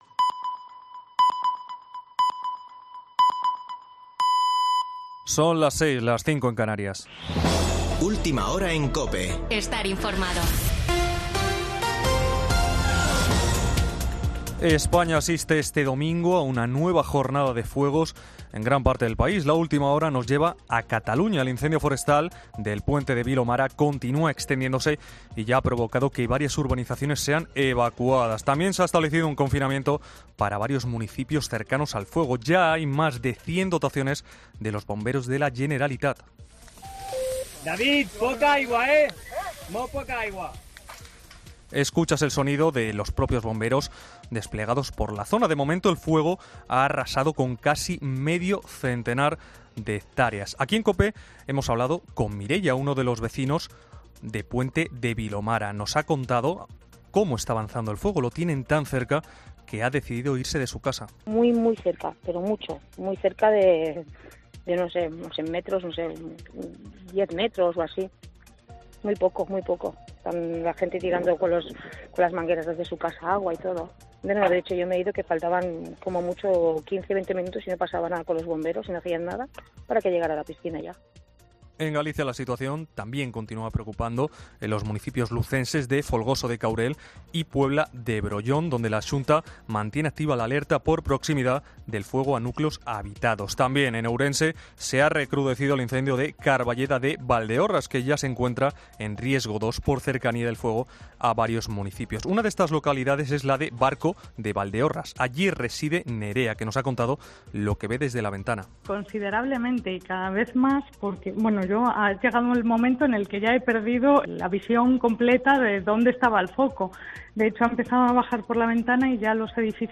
Boletín de noticias de COPE del 17 de julio de 2022 a las 18:00 horas